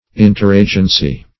Meaning of interagency. interagency synonyms, pronunciation, spelling and more from Free Dictionary.
interagency.mp3